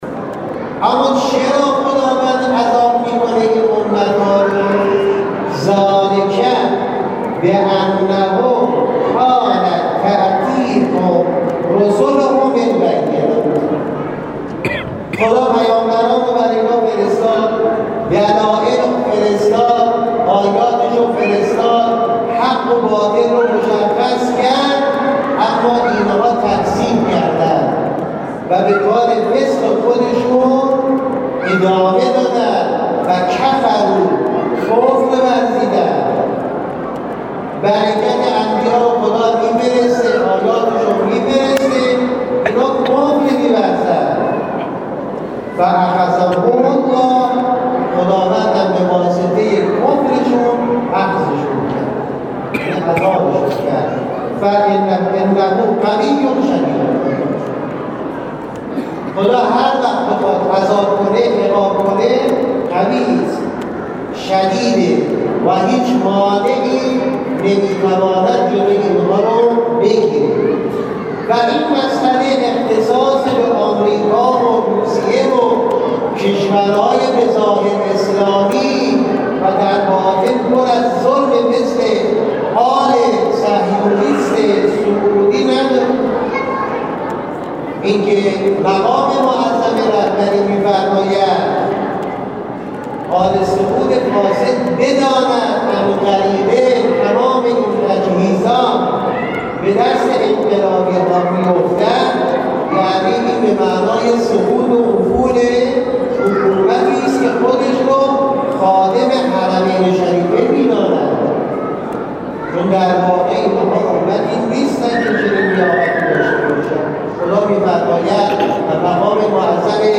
در نخستین نشست از سلسله همایش های افول آمریکا که در مسجد حضرت زینب(س) پردیسان برگزار شد